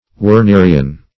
Wernerian \Wer*ne"ri*an\, a.